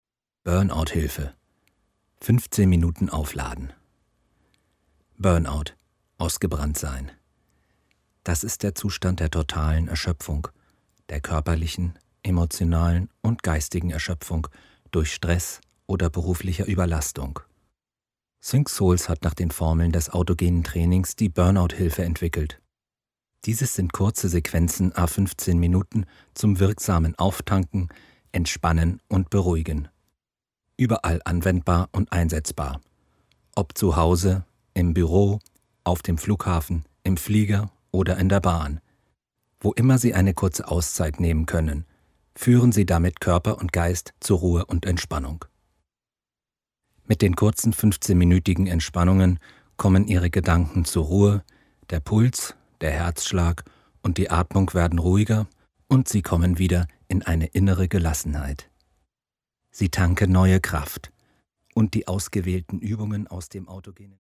iPhone / iPad / iPod Vorschau Einleitung und Erklärung